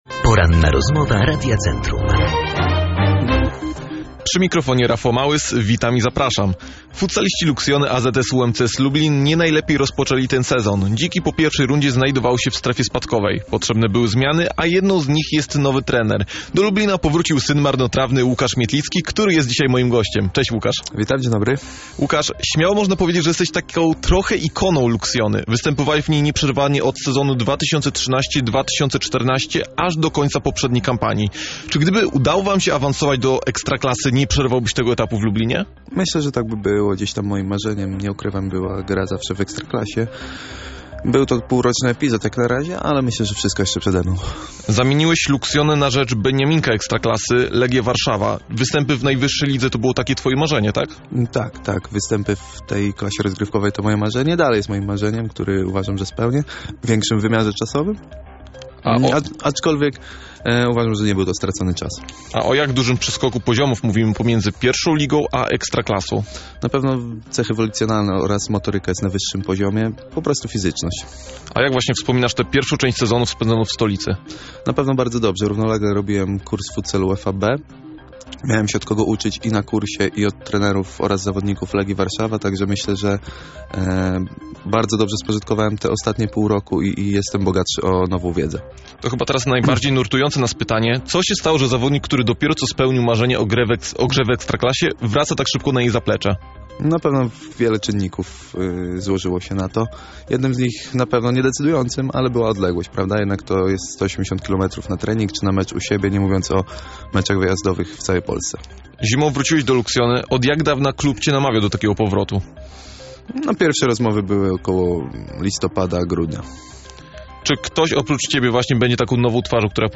Po-edycji-rozmowa.mp3